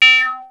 VARIOUS FILT 5.wav